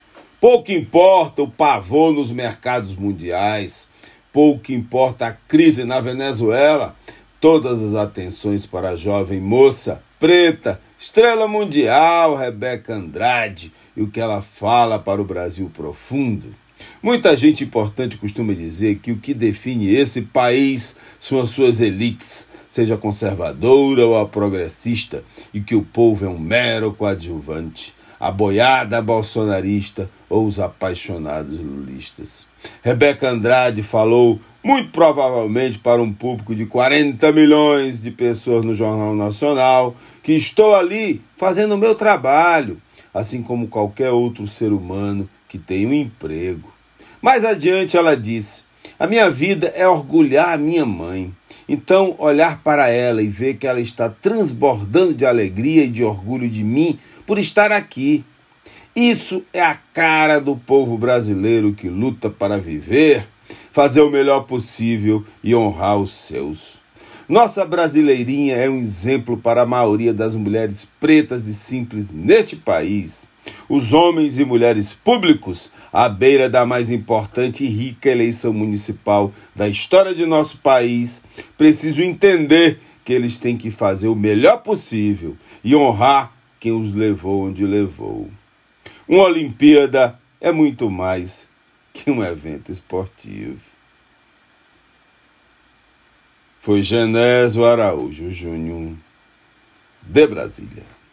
Comentário desta terça-feira
direto de Brasília.